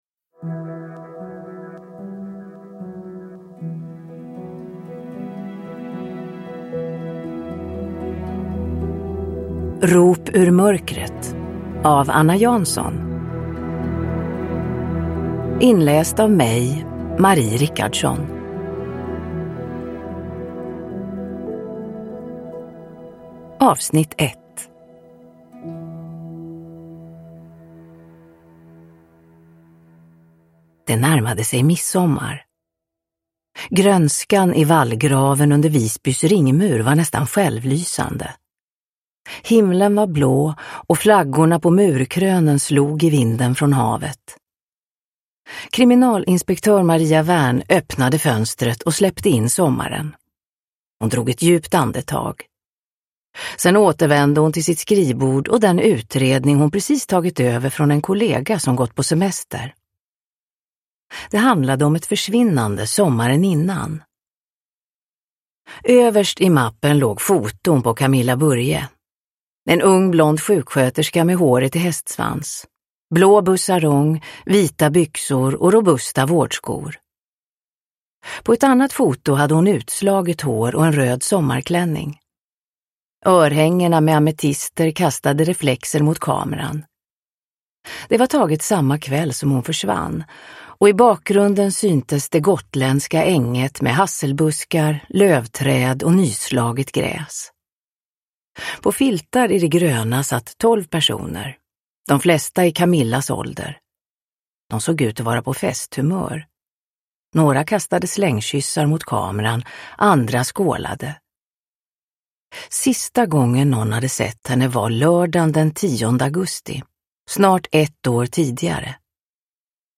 Rop ur mörkret - 1 – Ljudbok – Laddas ner
Uppläsare: Marie Richardson